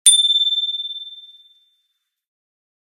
bicycle-bell_03
bell bells bicycle bike bright chime chimes clang sound effect free sound royalty free Memes